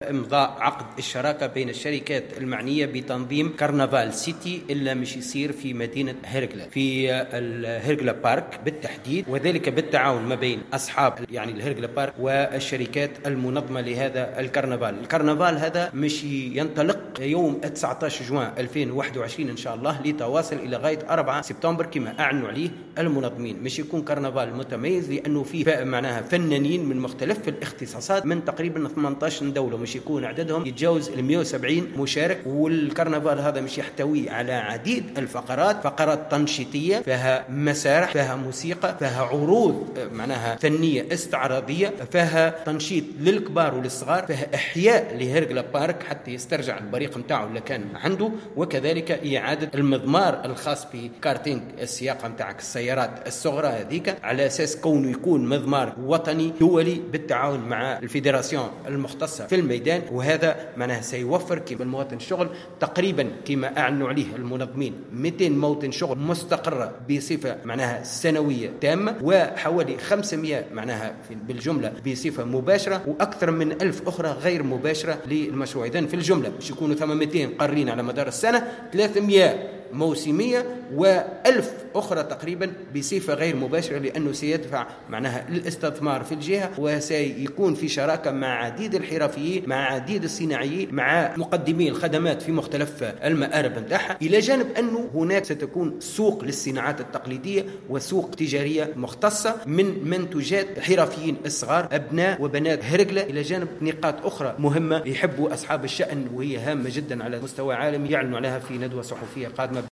المندوب الجهوي للسياحة بسوسة :توفيق القايد تحميل المشاركة علي